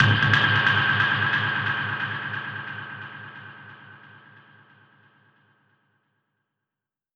Index of /musicradar/dub-percussion-samples/134bpm
DPFX_PercHit_C_134-10.wav